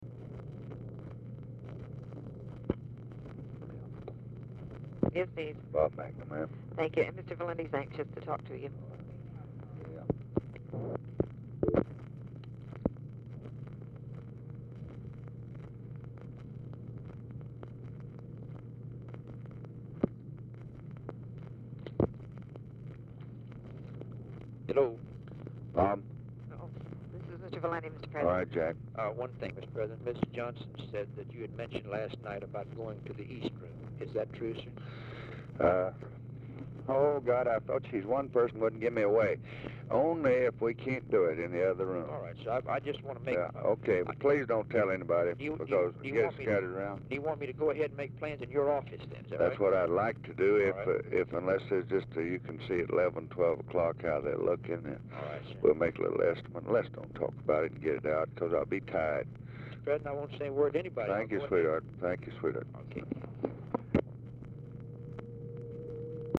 Telephone conversation # 7062, sound recording, LBJ and JACK VALENTI, 3/13/1965, 9:20AM
Format Dictation belt
Other Speaker(s) TELEPHONE OPERATOR